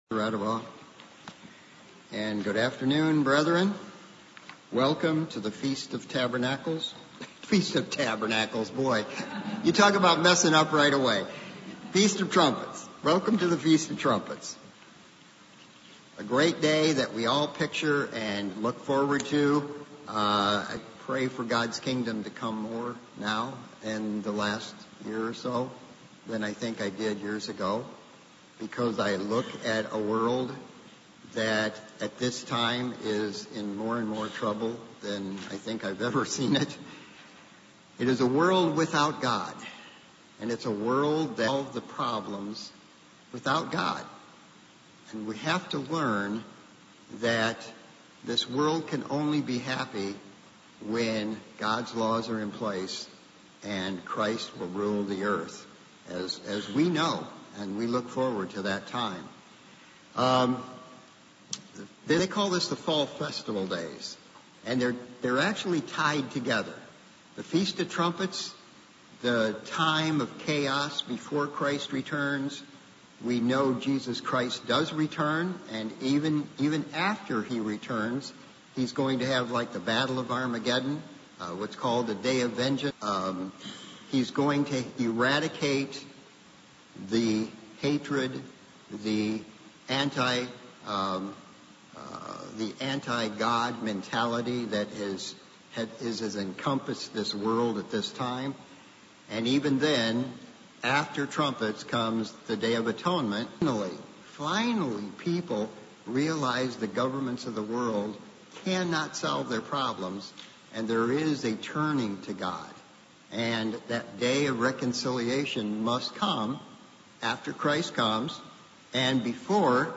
Trumpets sermon looking at how God will work with Israel to bring them into his kingdom